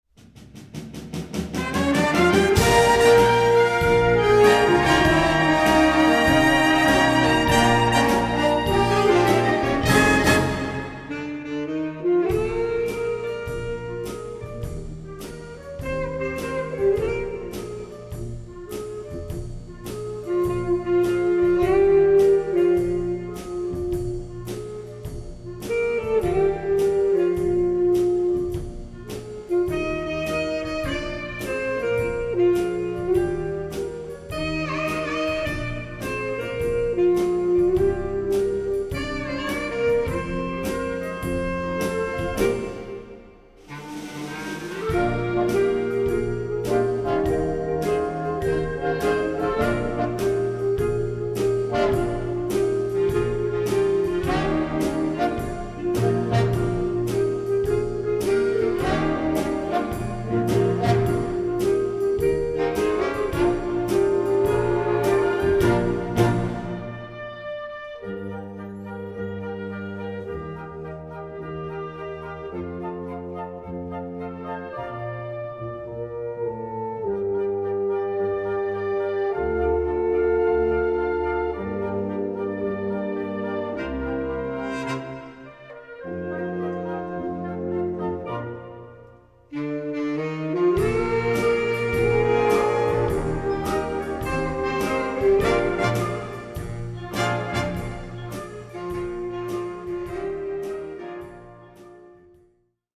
Recueil pour Harmonie/fanfare - Variétés françaises